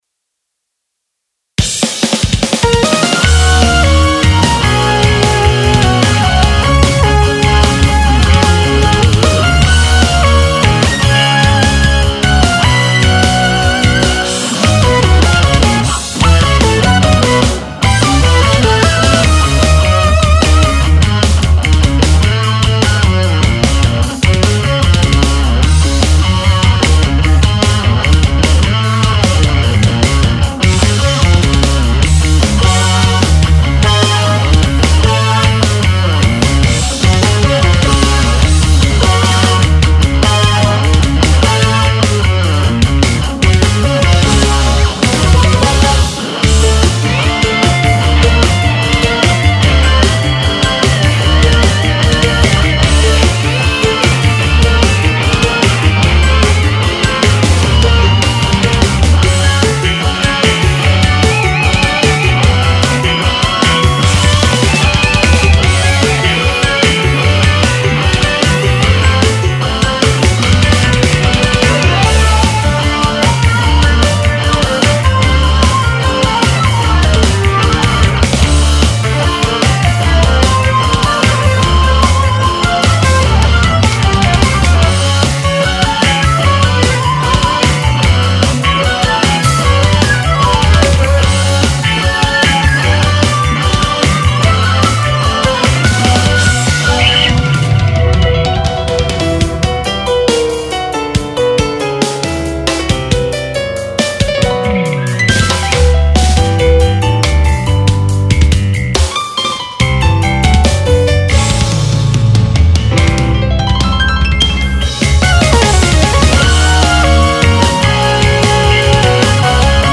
シンセサイザーをメインにちょっとハードロックよりのインスト、メロディック・フュージョン曲です。